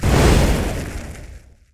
fireimpact02.wav